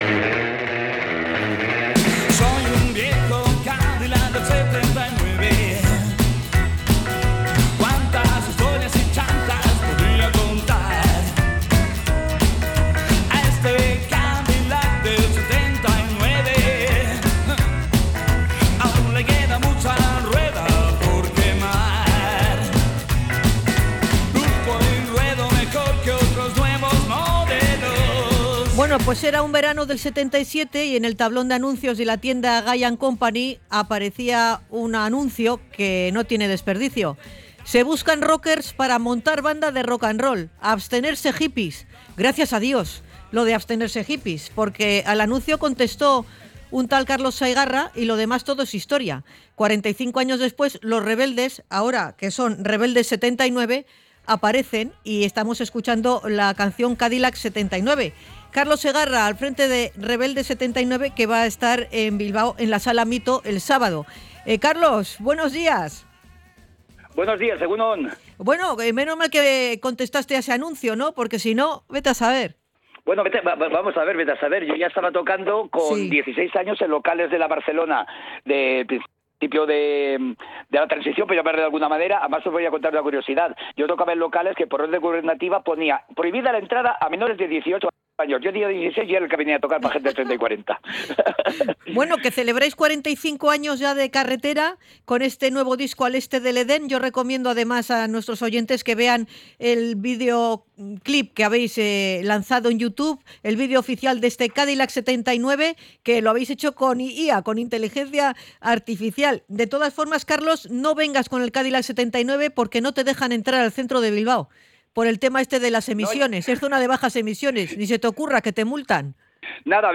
ENTREVISTA-CARLOS-SEGARRA.mp3